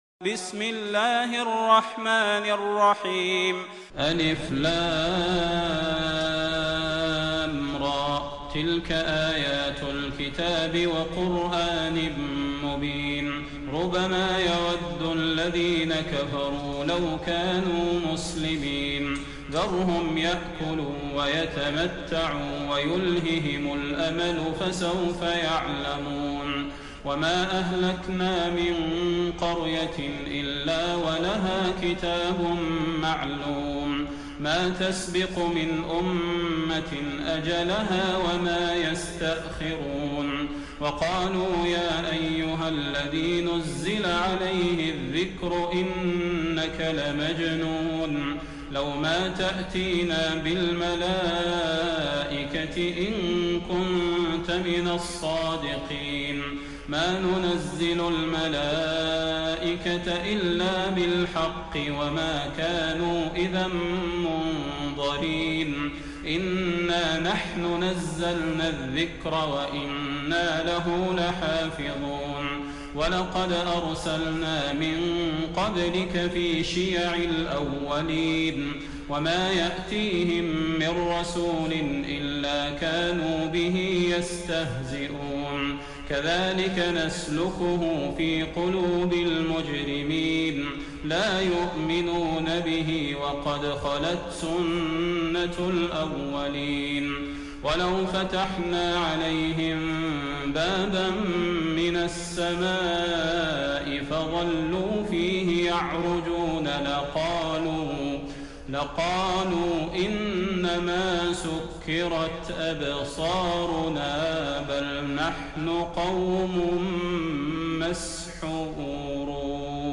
تراويح الليلة الثالثة عشر رمضان 1431هـ من سورتي الحجر كاملة و النحل (1-29) Taraweeh 13 st night Ramadan 1431H from Surah Al-Hijr and An-Nahl > تراويح الحرم النبوي عام 1431 🕌 > التراويح - تلاوات الحرمين